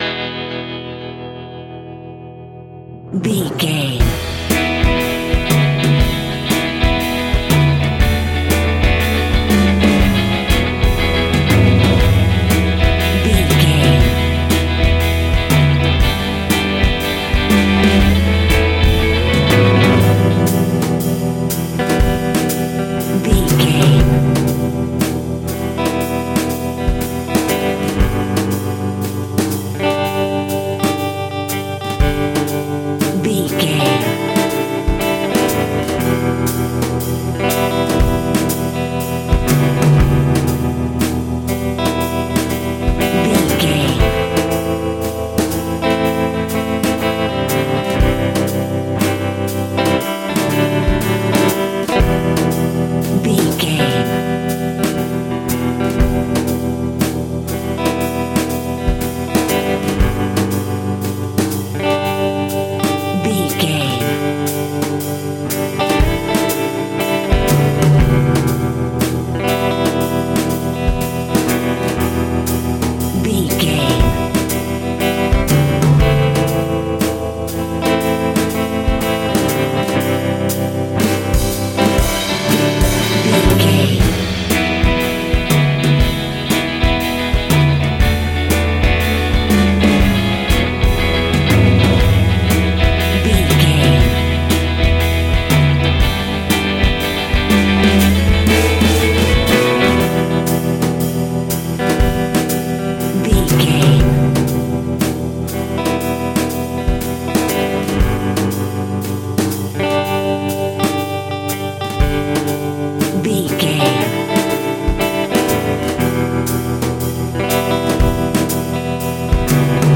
Aeolian/Minor
cool
uplifting
bass guitar
electric guitar
drums
cheerful/happy